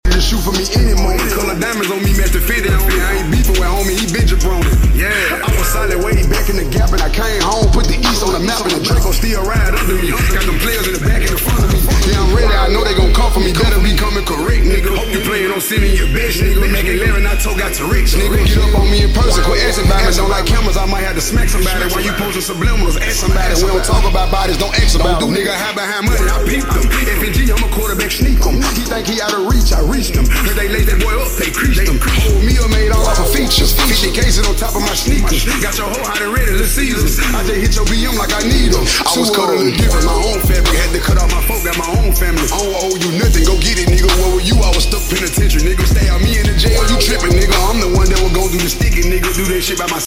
8D AUDIO